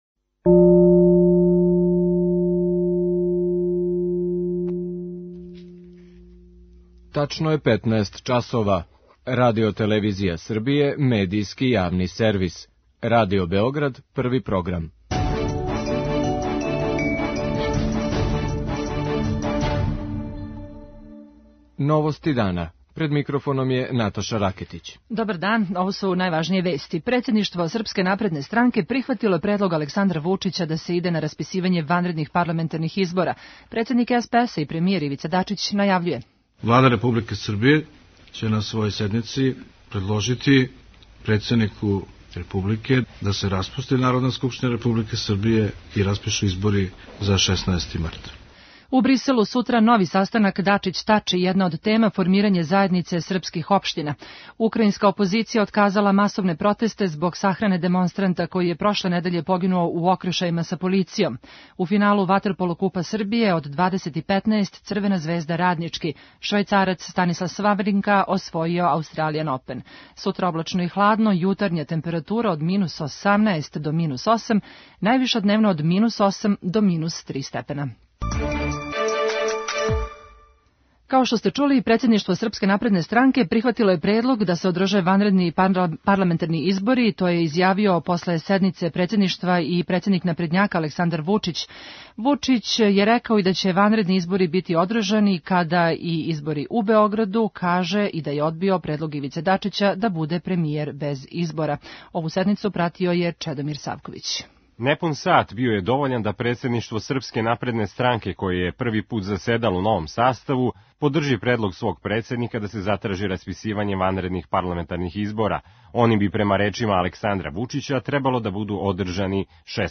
Избори су данас тема број један и на седницама руководстава осталих политичких странака, а о чему се тачно разговарало, извештавају наши репортери у Новостима дана.